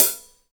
12 HAT 2.wav